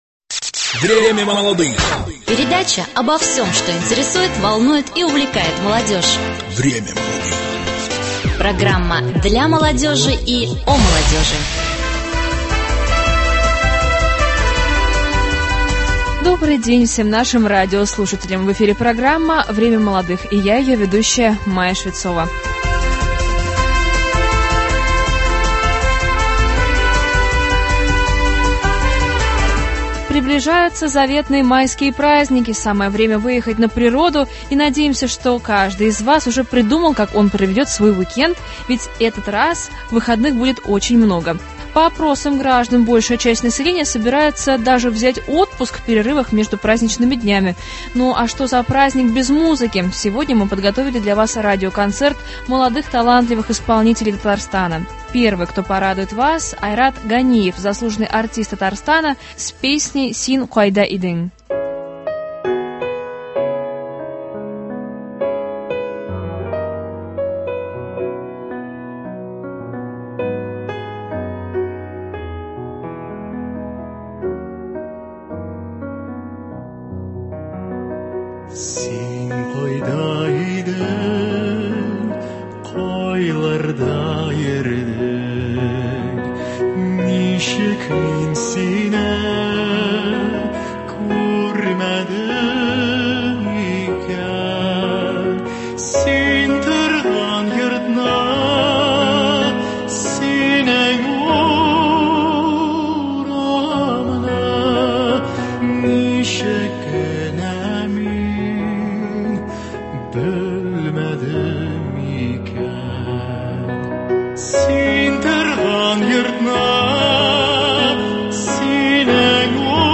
Концерт современных молодых музыкантов.